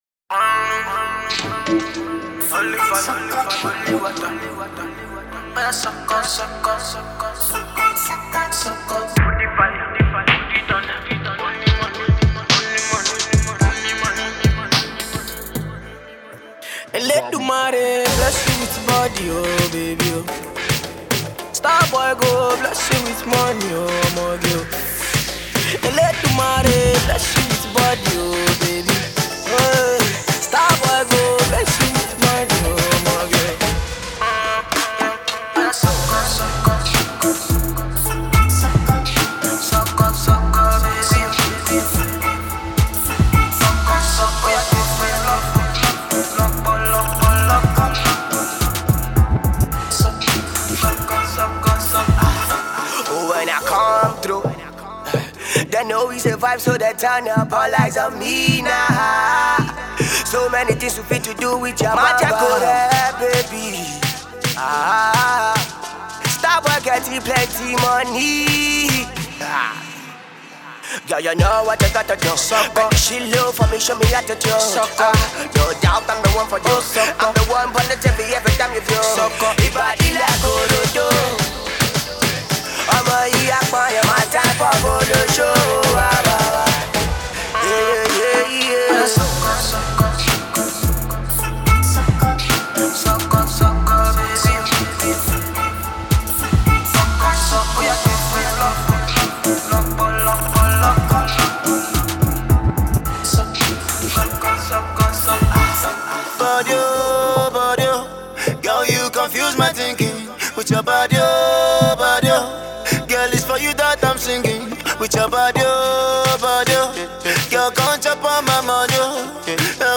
hiphop remix
He fuses afrobeats and hiphop in the new track.